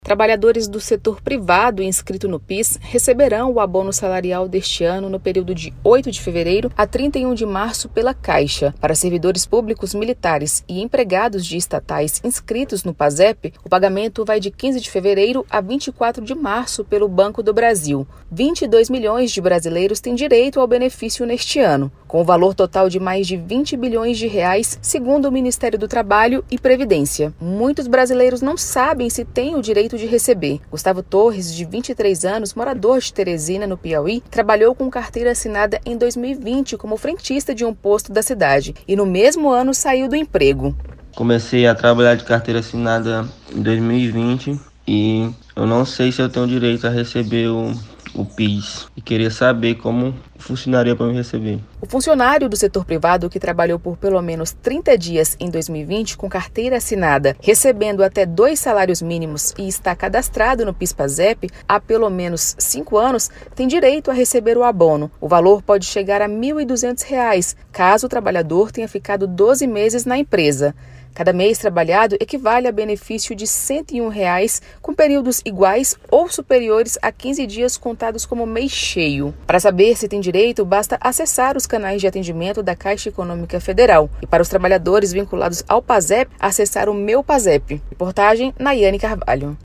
MATÉRIA EM ÁUDIO